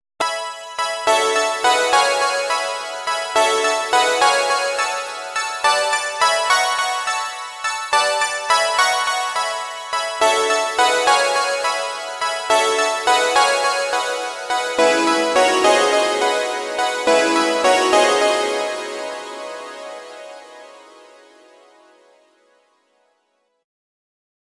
Replacing “sine-table” with “tri-table” (triangle) in the code here gives a brighter sound …